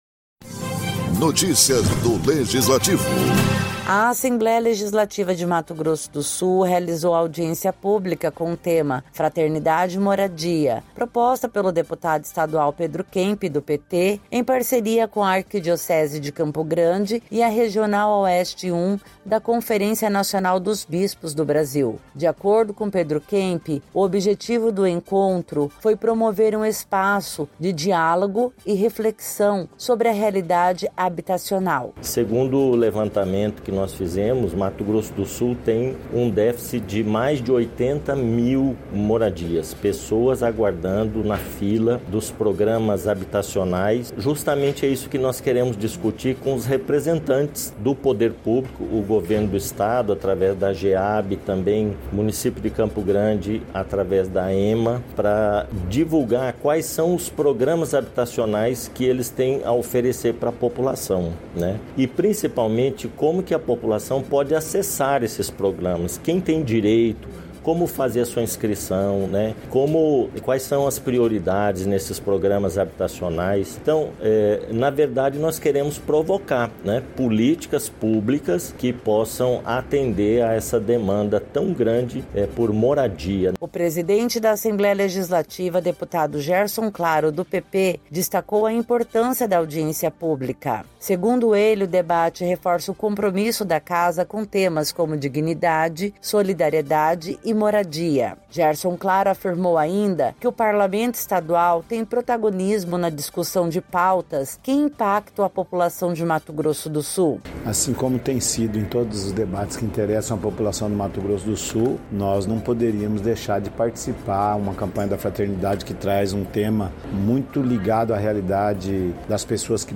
A Assembleia Legislativa de Mato Grosso do Sul realizou, em parceria com a Regional Oeste 1 da Conferência Nacional dos Bispos do Brasil e a Arquidiocese de Campo Grande, a audiência pública “Fraternidade e Moradia”, no plenário Júlio Maia. O encontro integra a Campanha da Fraternidade e propõe a reflexão da sociedade e das autoridades sobre a importância da moradia digna no Brasil.